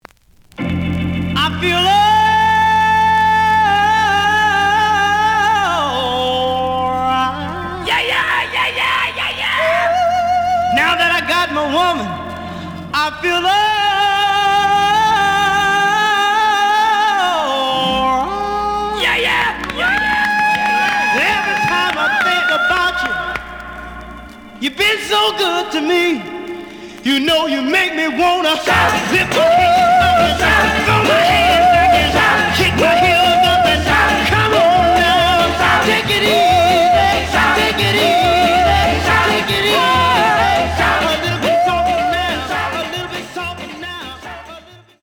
試聴は実際のレコードから録音しています。
●Genre: Rhythm And Blues / Rock 'n' Roll
EX-, VG+ → 傷、ノイズが多少あるが、おおむね良い。